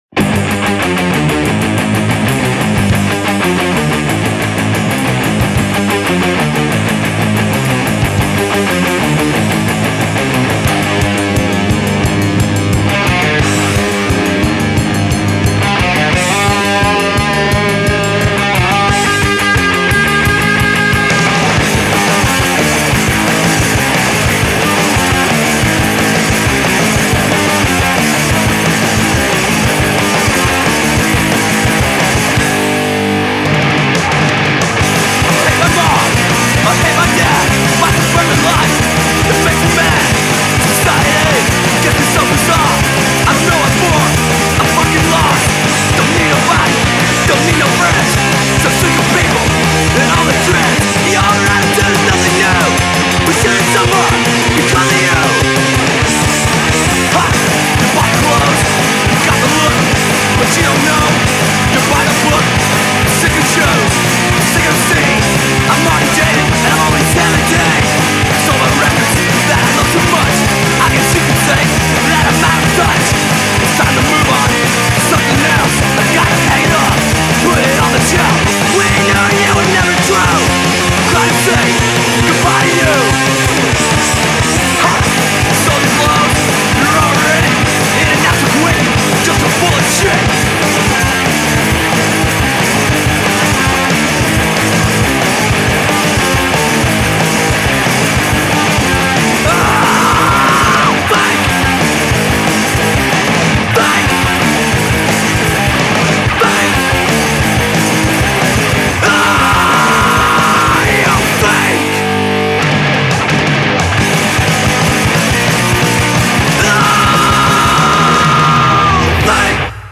Classic 1980s style hardcore punk done correctly.